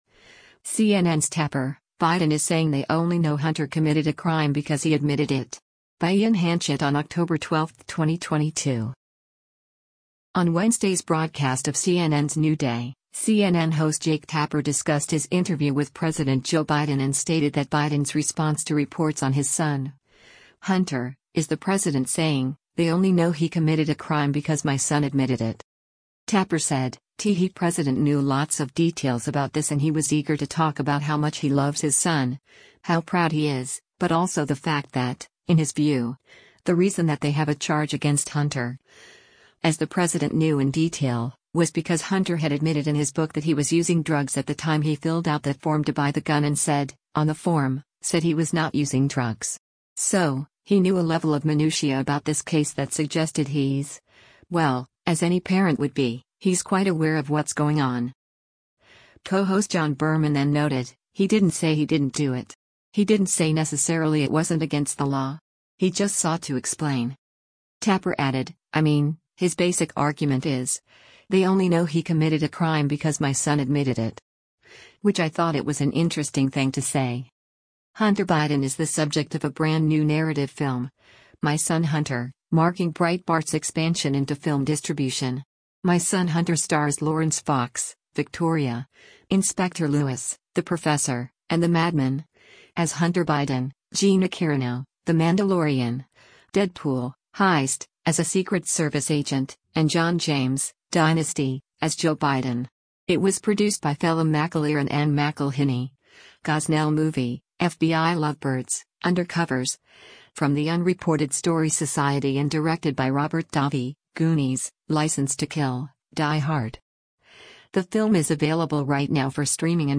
On Wednesday’s broadcast of CNN’s “New Day,” CNN host Jake Tapper discussed his interview with President Joe Biden and stated that Biden’s response to reports on his son, Hunter, is the President saying, “they only know he committed a crime because my son admitted it.”